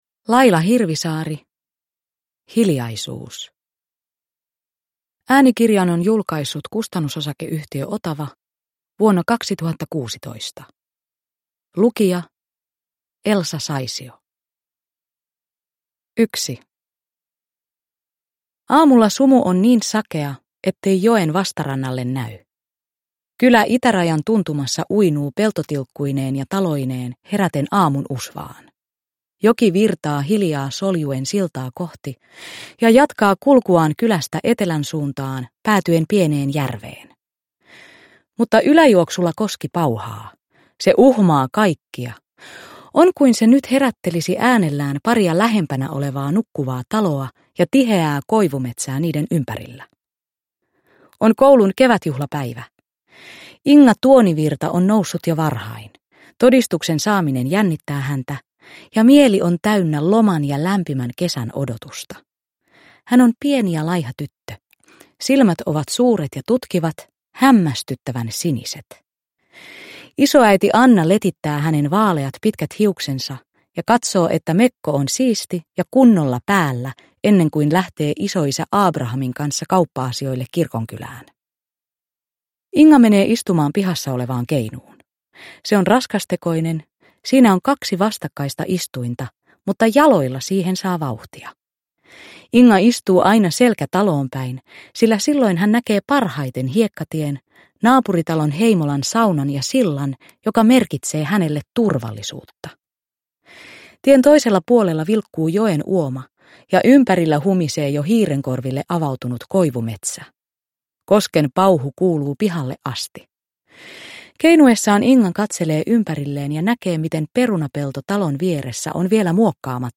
Hiljaisuus – Ljudbok – Laddas ner
Uppläsare: Elsa Saisio